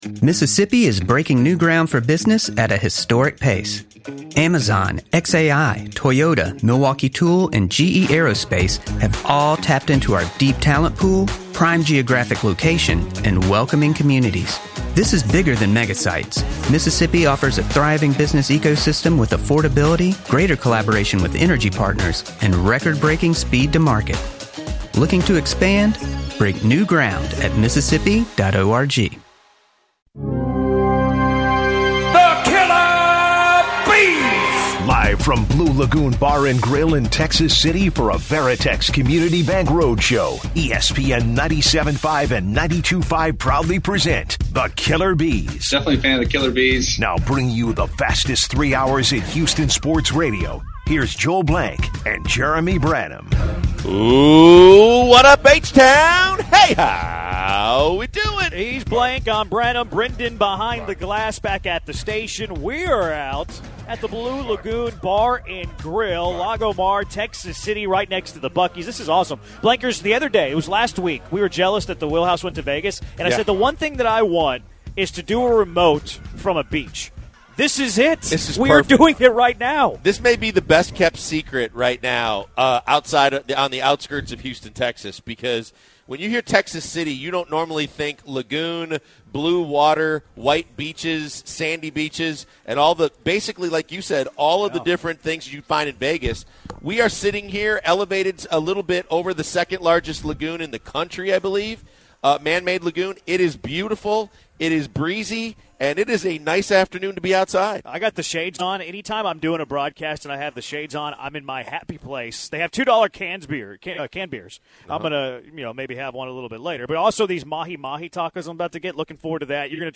The Killer B's spending the first hour interacting with listeners on the Astros game 3 loss of the World Series